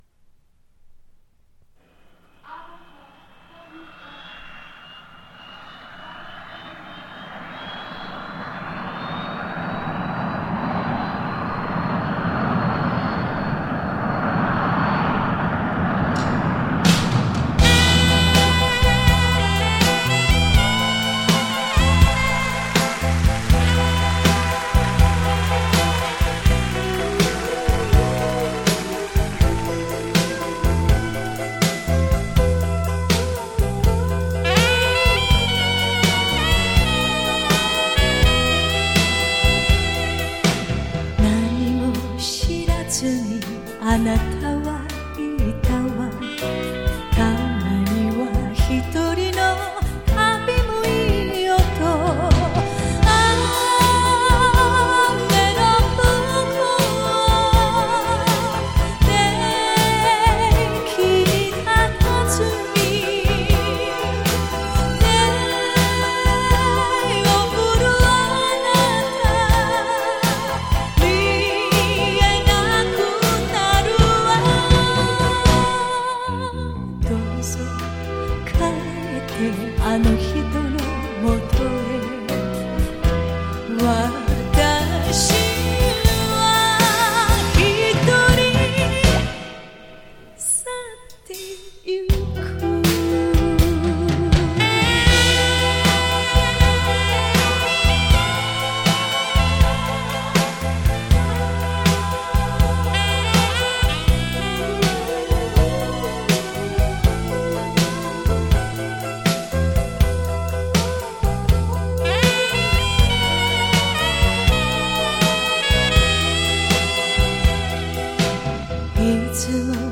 LIVE
1985年录音，为尊重当年现场(Live)收音
绝美音色，原音重现。